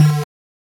新鲜包装 " Bass03
Tag: 低音 畸变 电子 打击乐器